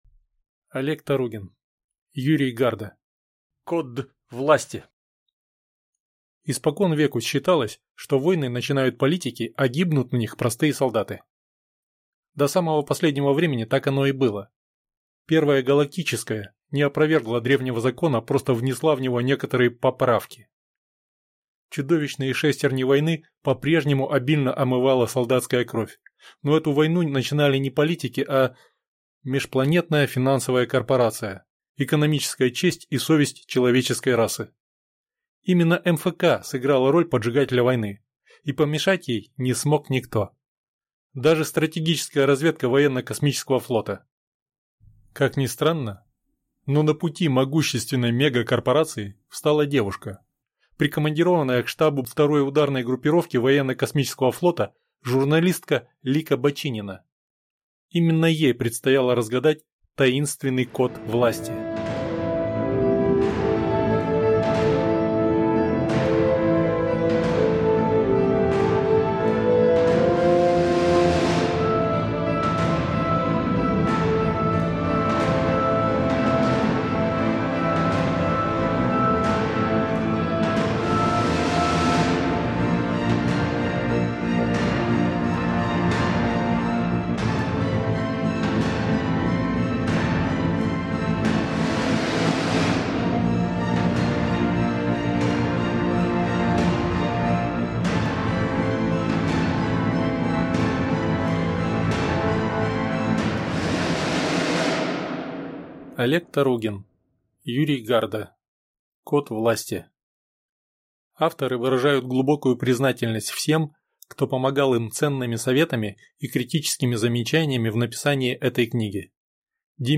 Аудиокнига Код власти | Библиотека аудиокниг